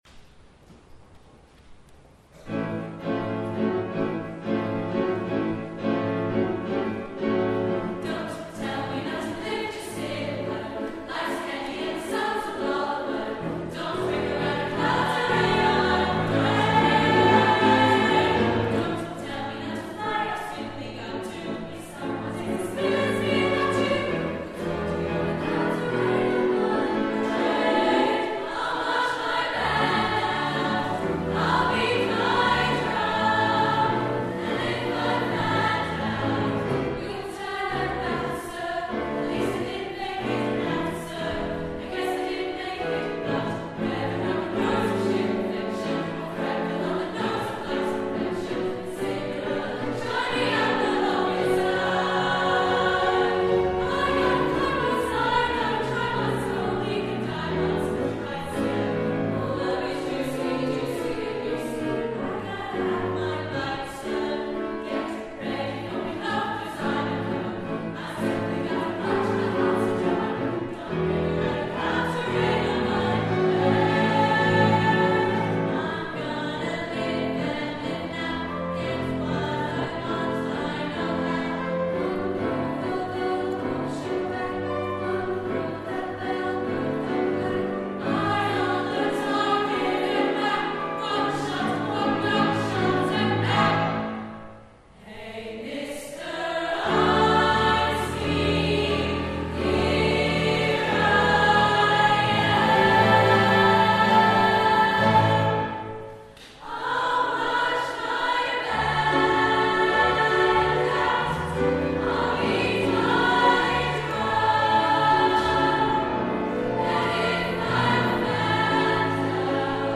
At the Simon Balle Choral Concert 2017